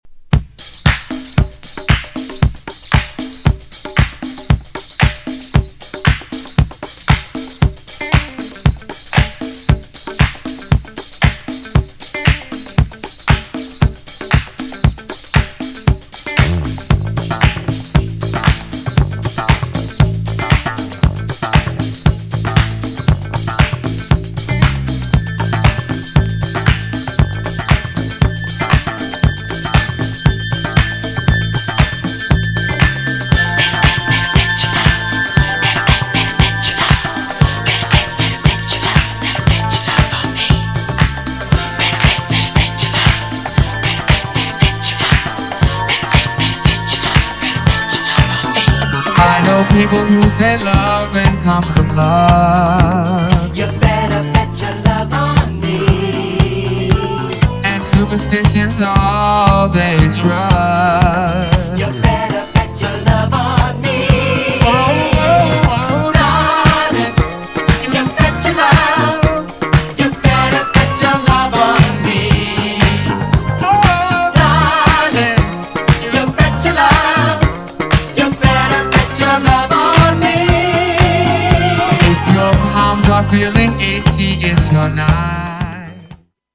GENRE Dance Classic
BPM 111〜115BPM
エレクトロ